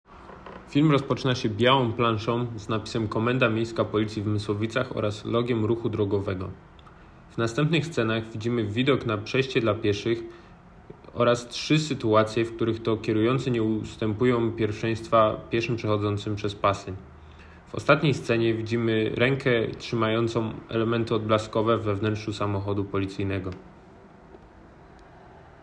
Nagranie audio nurd_audiodeskrypcja.m4a
Opis nagrania: audiodeskrypcja do filmu